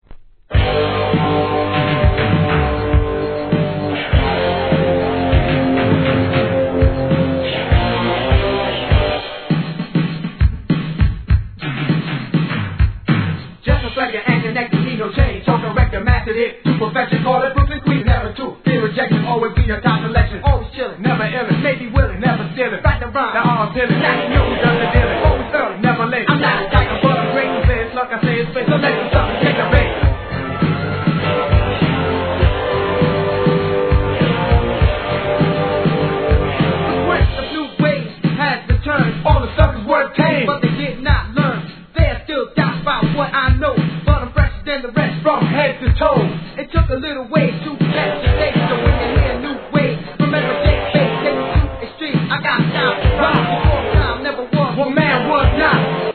HIP HOP/R&B
当時のRUN-DMCを髣髴させる1986年OLD SCHOOL!